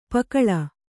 ♪ pakaḷa